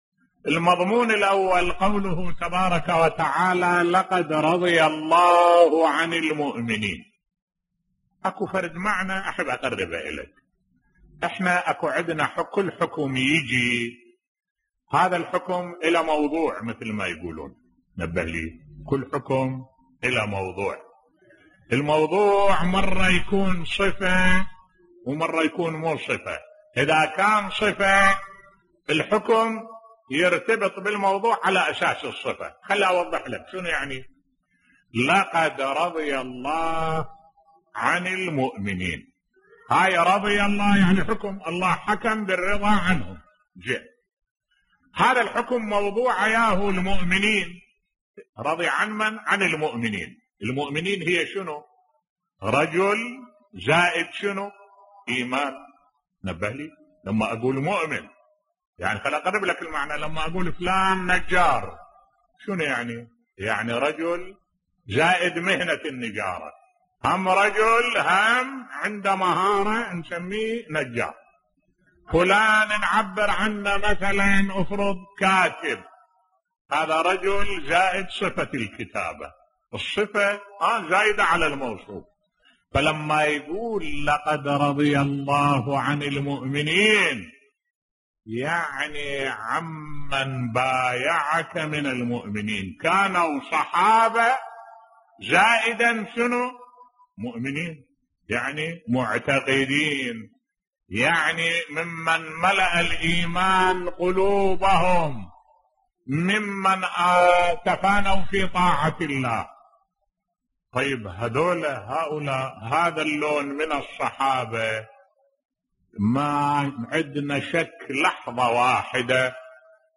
ملف صوتی الله لم يرض عن جميع من بايع تحت الشجرة بصوت الشيخ الدكتور أحمد الوائلي